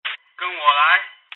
radio_followme.mp3